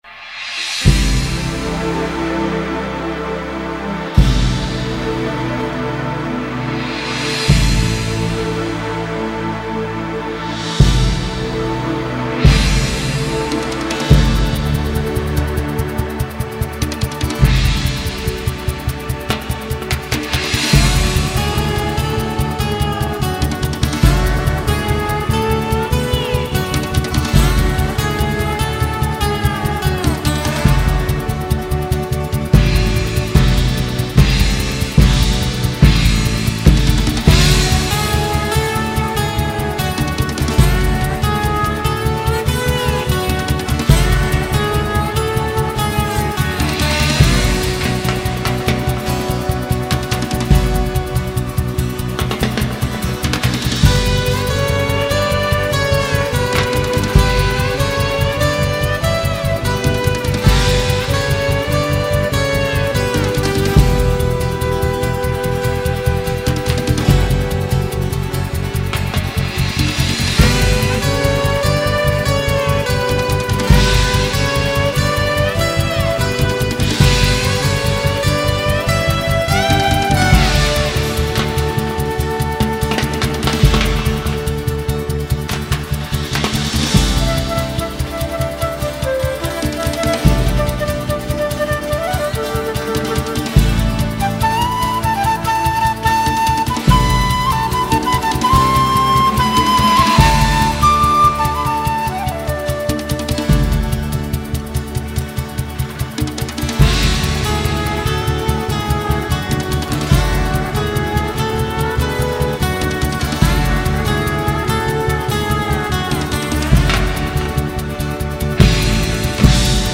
2921   03:56:00   Faixa: 9    Mpb